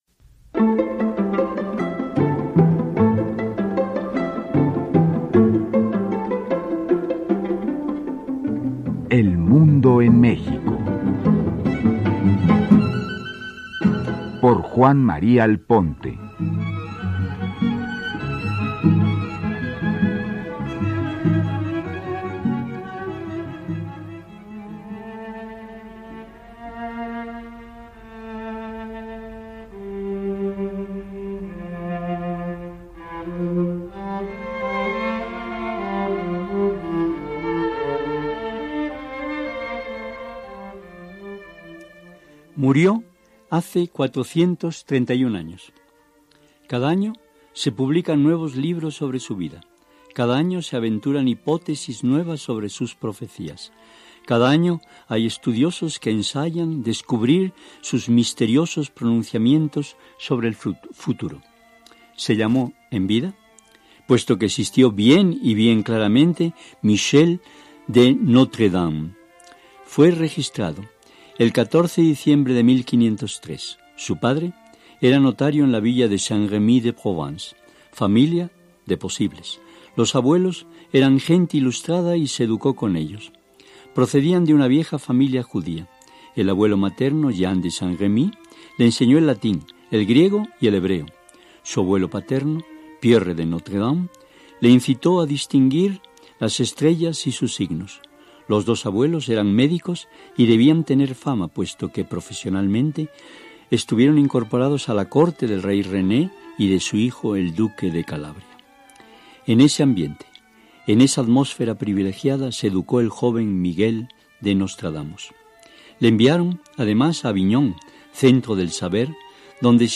Escucha una semblanza de Nostradamus en el programa de Juan María Alponte, “El mundo en México”, transmitido en 2001.